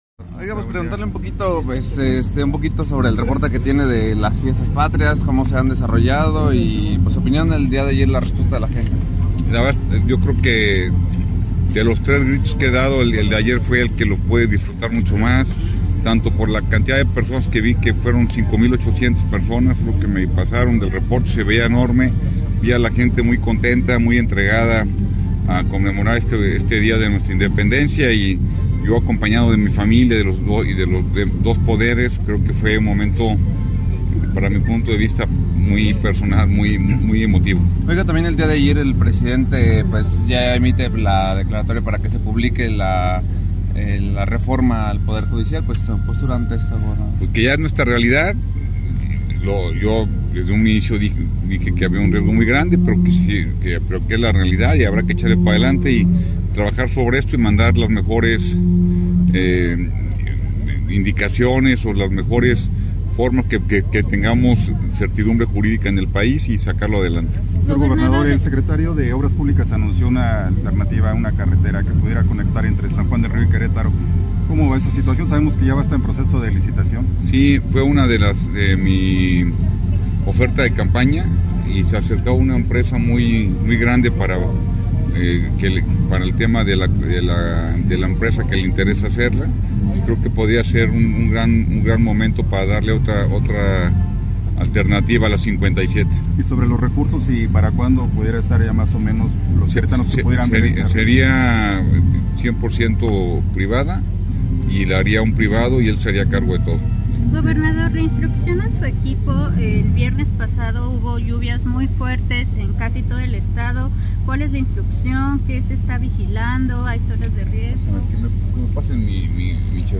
Entrevista con el Gobernador Mauricio Kuri. Vía alterna a SJR y otros temas